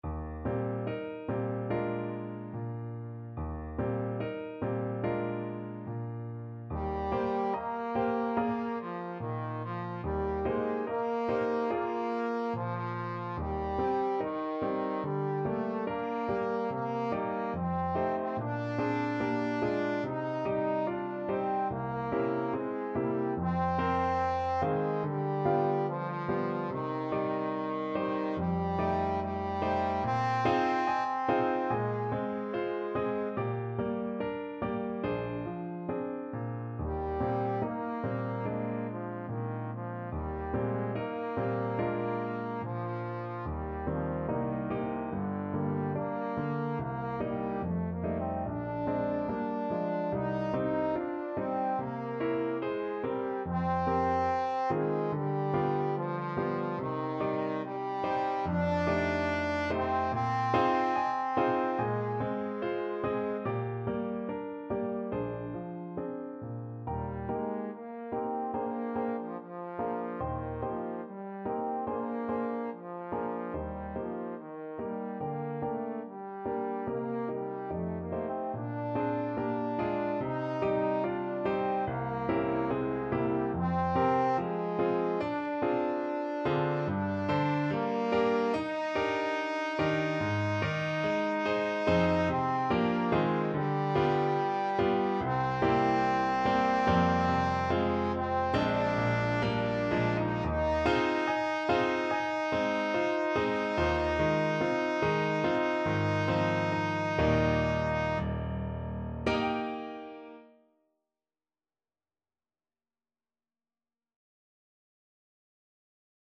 ~ = 72 In moderate time
4/4 (View more 4/4 Music)
Classical (View more Classical Trombone Music)